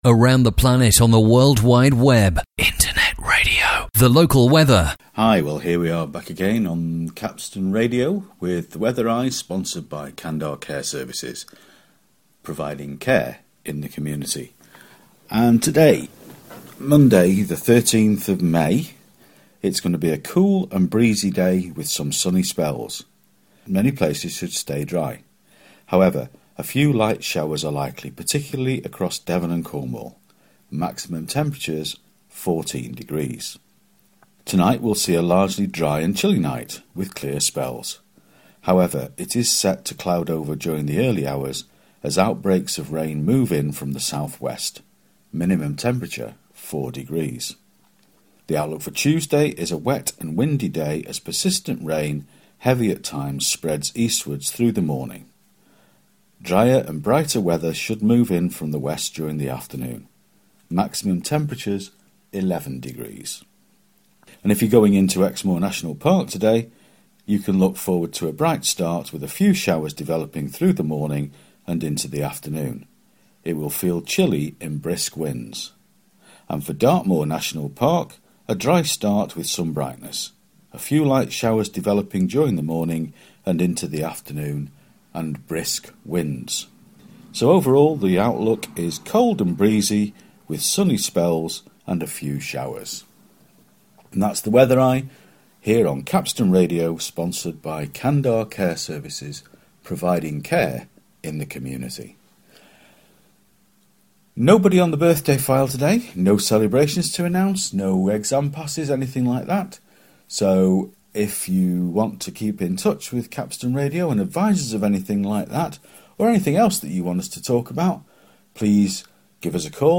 Today's weather outlook for North Devon